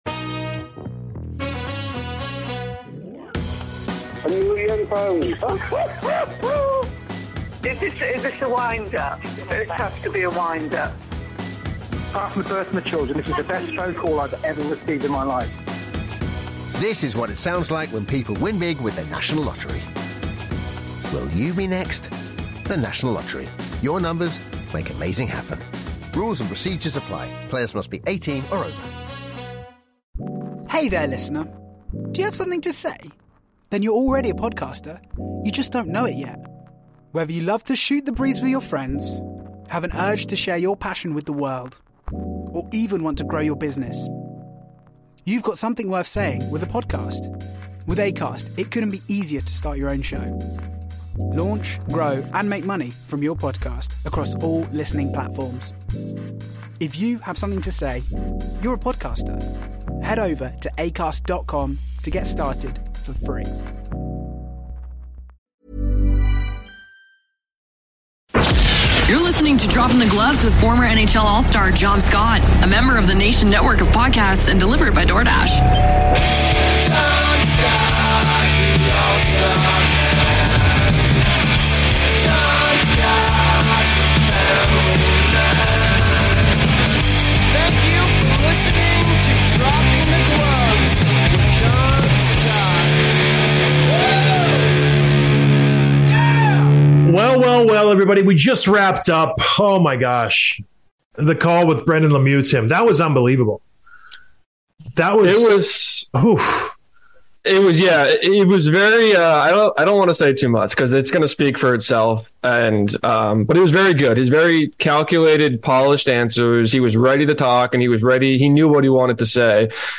Interview with Brendan Lemieux, LA Kings